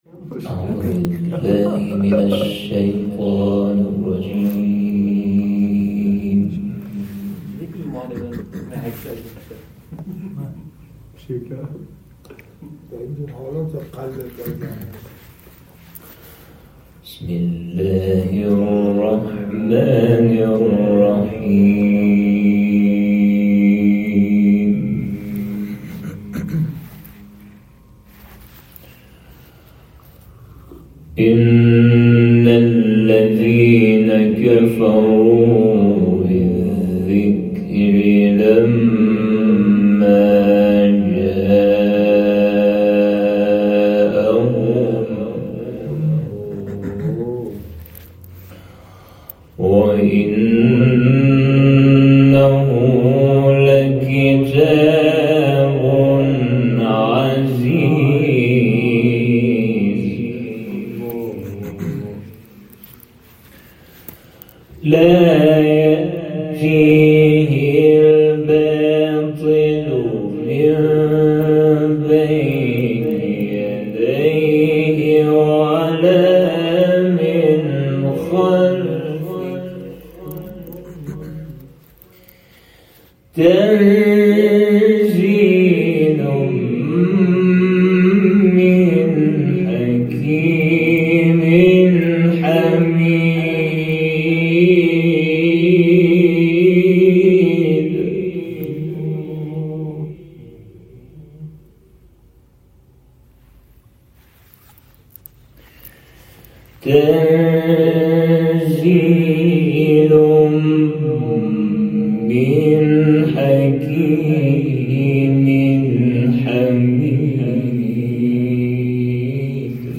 تلاوت 41-44 فصلت